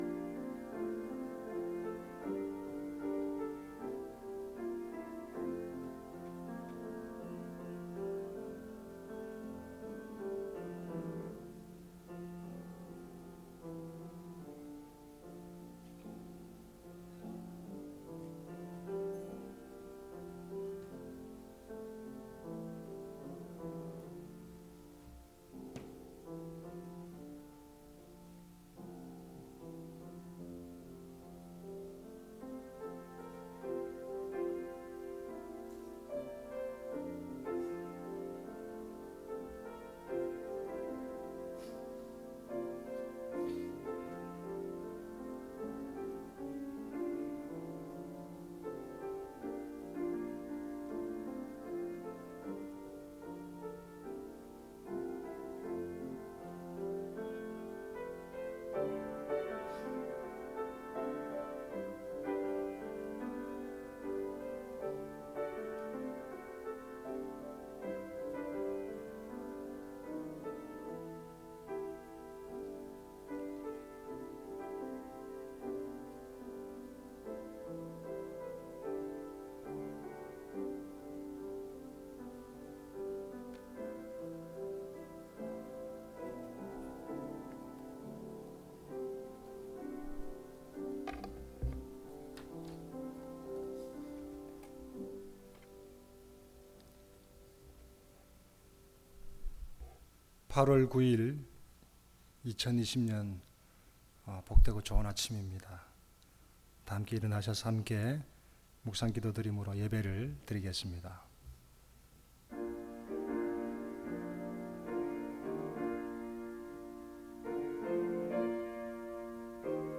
주일 설교